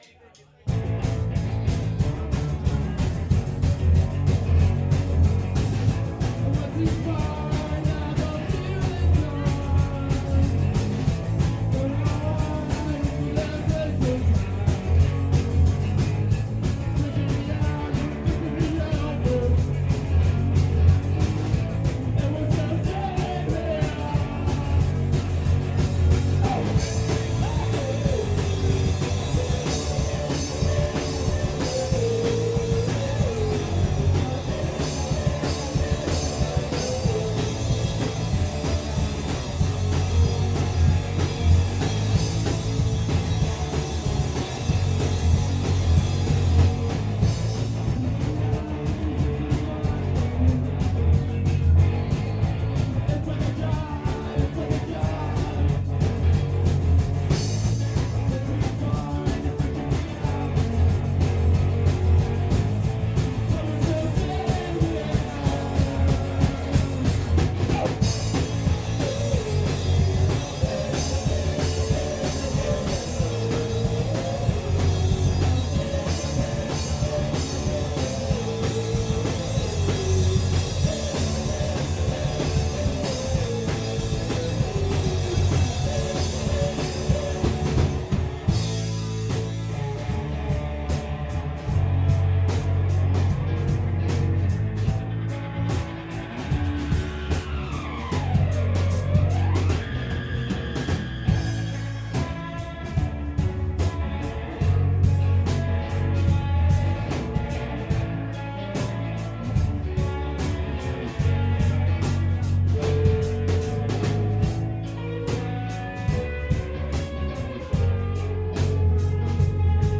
Garage Rock